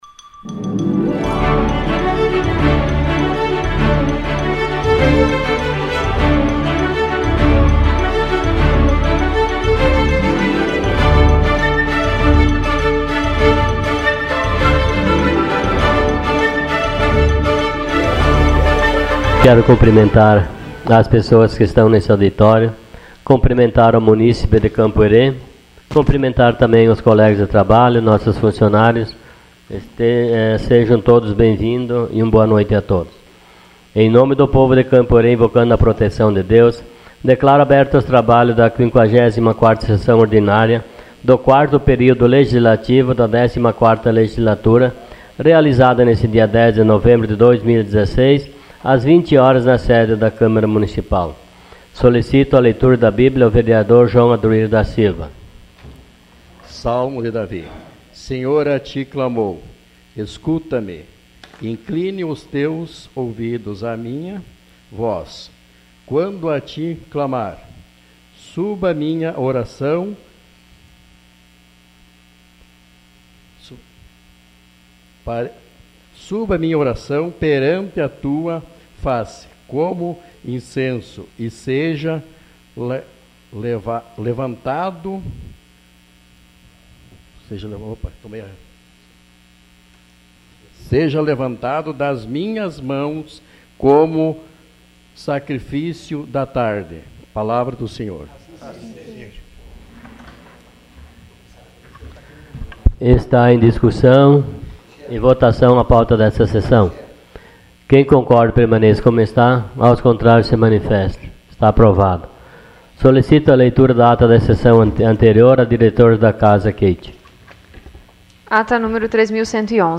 Sessão Ordinária dia 10 de novembro de 2016.